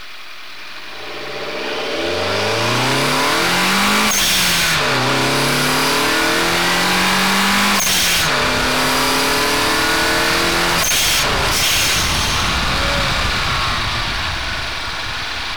Hear a GTiR
The engine has a Trust Airfilter and a HKS Super Seq. Blow-Off Valve.